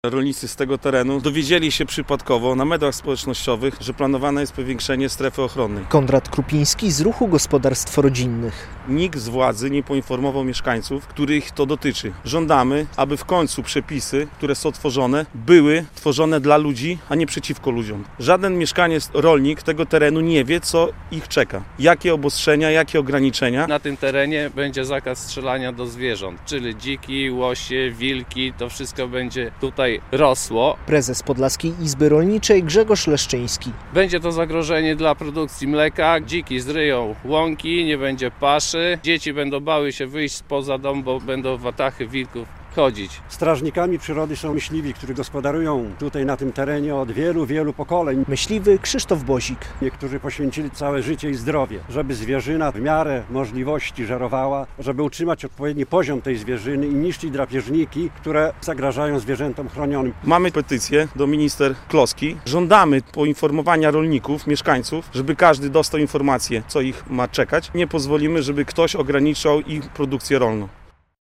Rolnicy protestują w sprawie stref ochronnych zwierzyny łownej - relacja